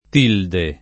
tilde [ t & lde ] s. f. o m.